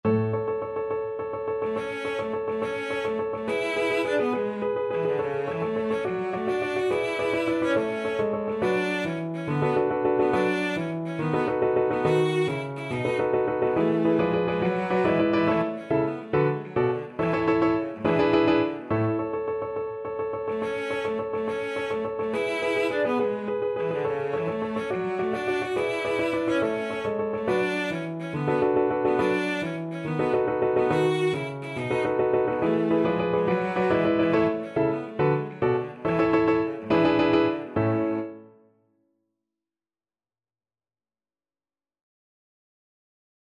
Classical Mendelssohn, Felix Symphony No. 4 'Italian' First Movement Main Theme Cello version
Cello
6/8 (View more 6/8 Music)
A major (Sounding Pitch) (View more A major Music for Cello )
Allegro Vivace .=140 (View more music marked Allegro)
Classical (View more Classical Cello Music)